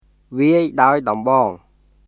[ヴィアイ・ダオイ・ドンボーン　viˑəi daoi dɔmbɔːŋ]